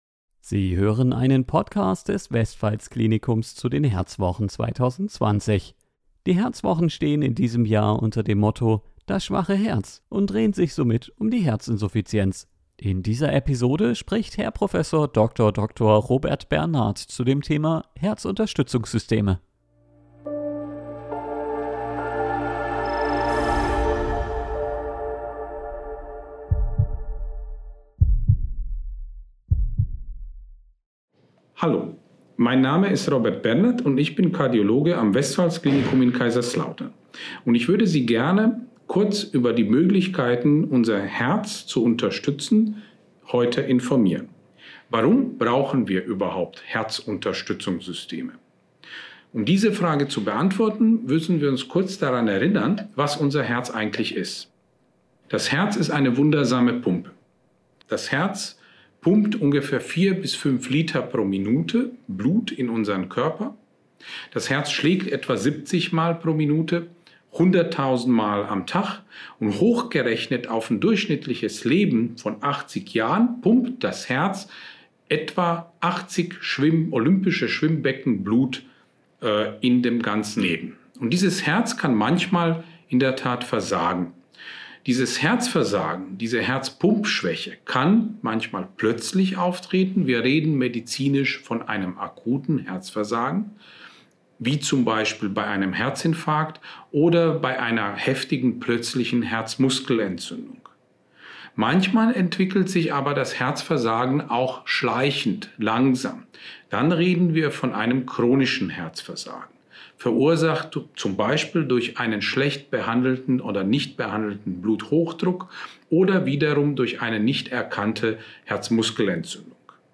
Herzwochen 2020 am Westpfalz-Klinikum Kaiserslautern